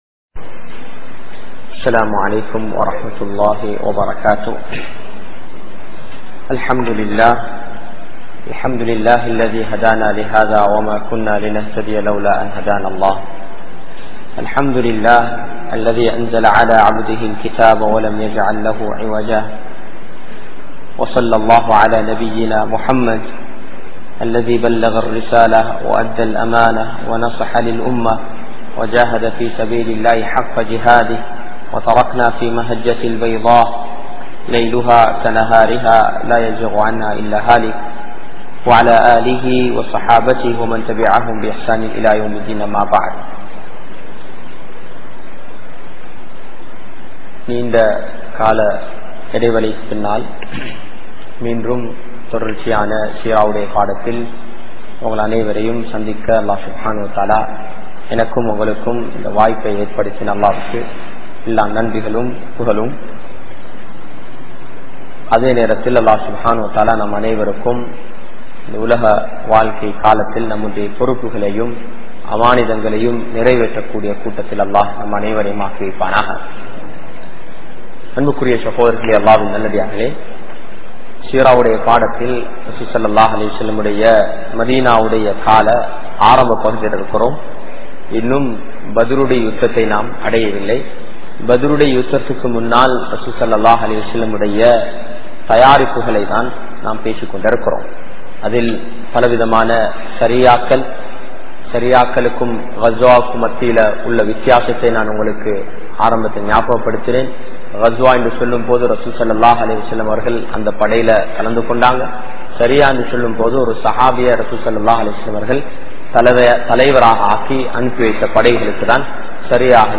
Seerathul Rasool(Lesson 50) | Audio Bayans | All Ceylon Muslim Youth Community | Addalaichenai
Muhiyadeen Jumua Masjith